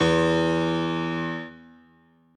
b_basspiano_v127l1o3e.ogg